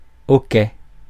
Ääntäminen
Ääntäminen France (Paris): IPA: [o.kɛ] Tuntematon aksentti: IPA: /ɔ.kɛ/ IPA: /ʔɔ.kɛ/ Haettu sana löytyi näillä lähdekielillä: ranska Käännös 1. luksumine 2. luksatus Suku: m .